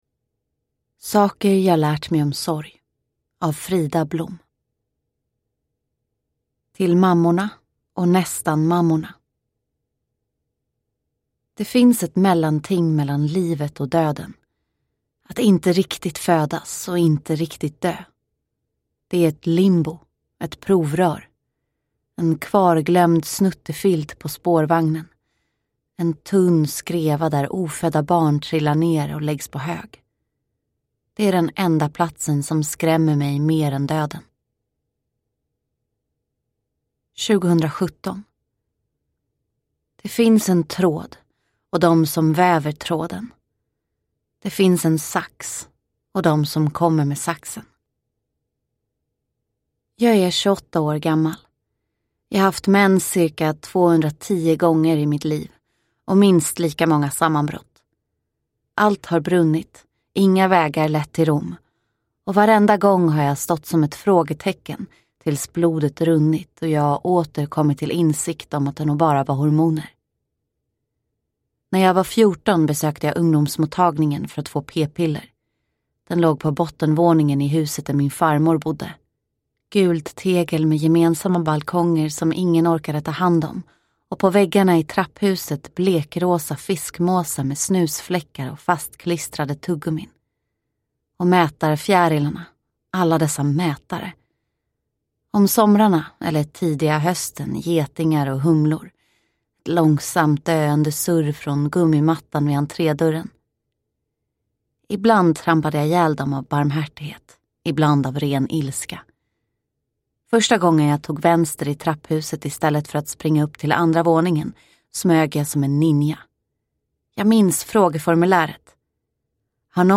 Saker jag lärt mig om sorg – Ljudbok – Laddas ner